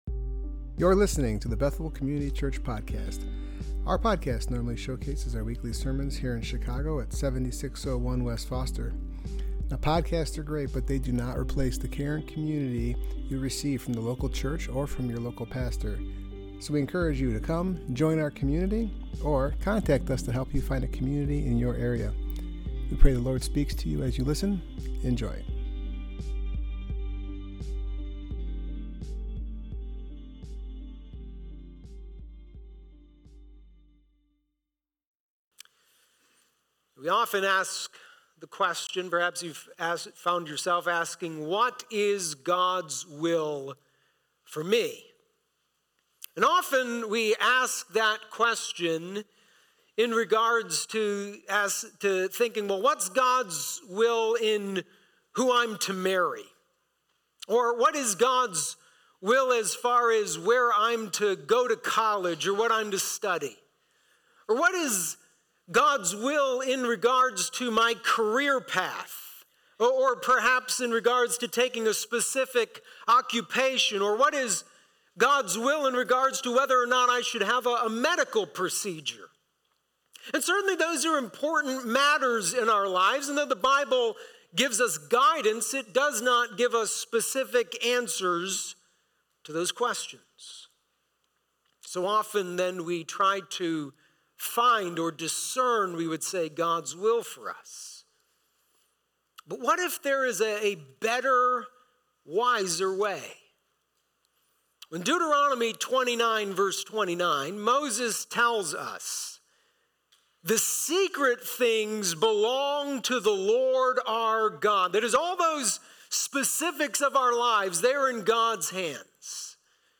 Passage: Proverbs 3:1-12 Service Type: Worship Gathering